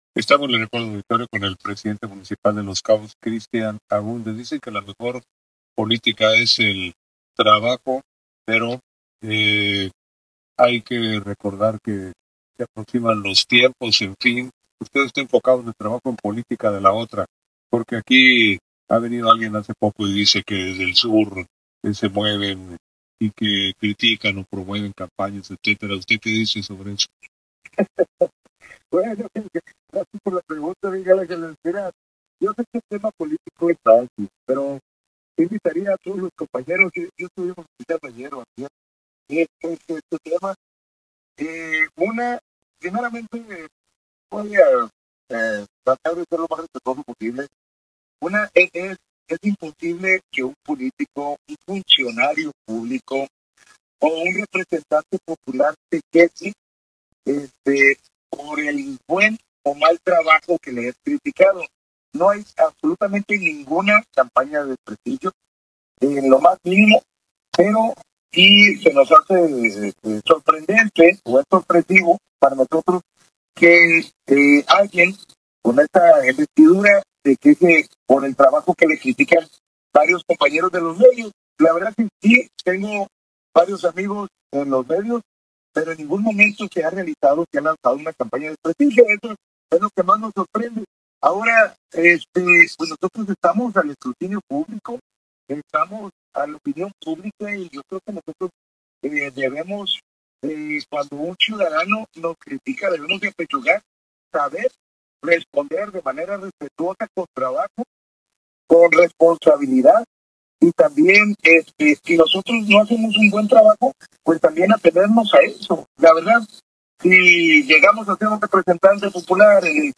ENTREVISTA-CA-PANORAMA-1712.mp3